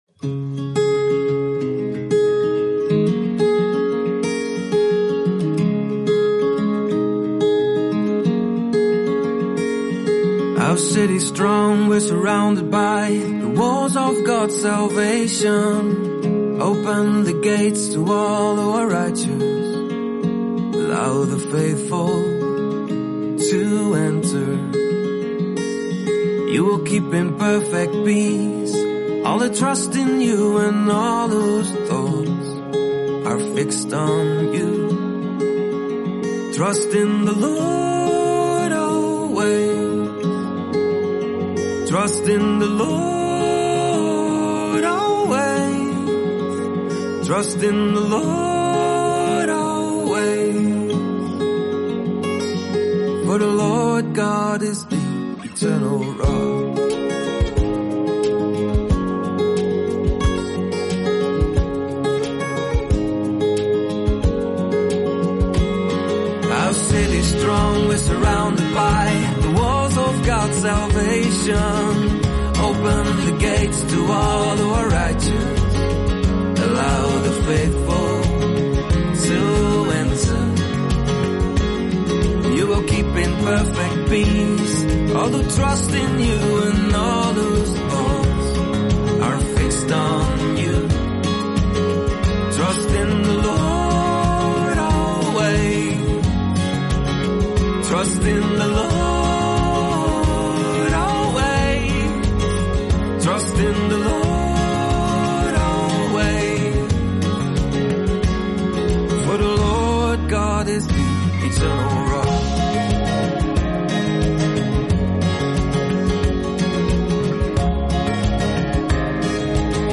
Then this song is especially for you.
Hear the Words of the Father sung to and over you.